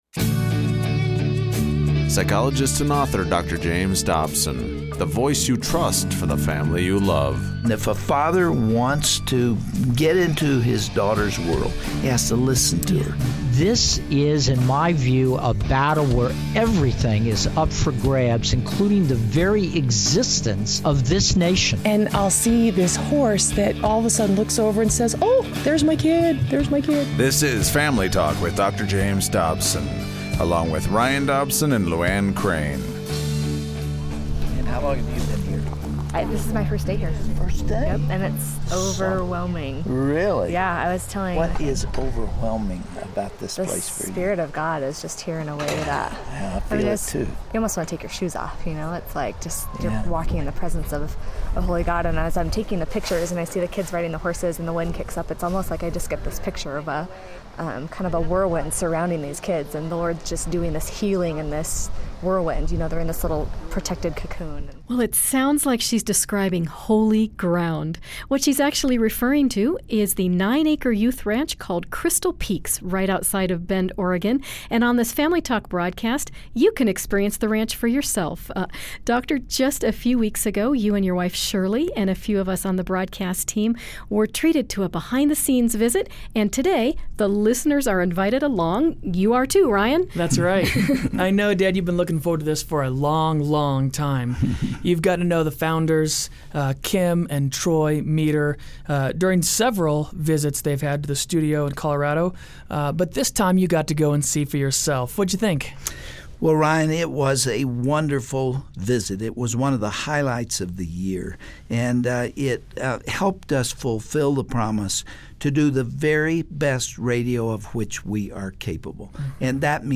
The broadcast studio team goes on the road to bring you a first-hand look at Crystal Peaks Youth Ranch, the Oregon horse sanctuary that provides hope and healing to so many.